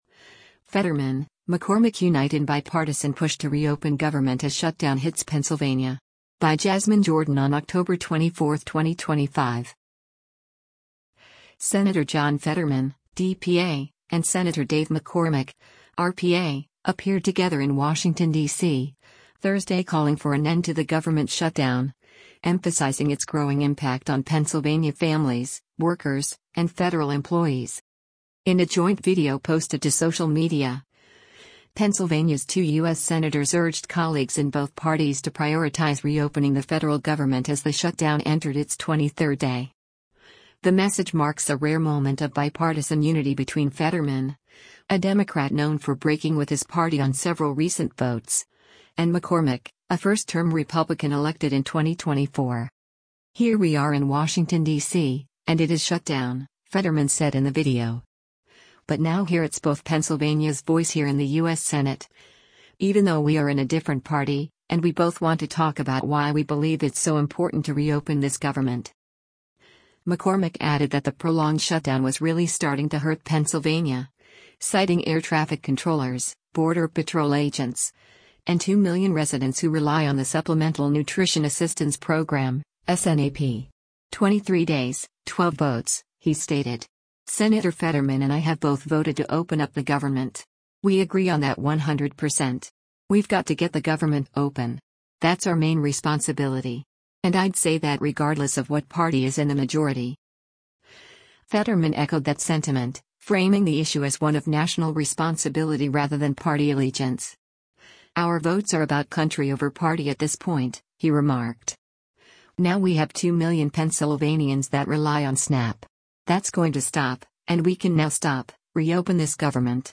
Sen. John Fetterman (D-PA) and Sen. Dave McCormick (R-PA) appeared together in Washington, D.C., Thursday calling for an end to the government shutdown, emphasizing its growing impact on Pennsylvania families, workers, and federal employees.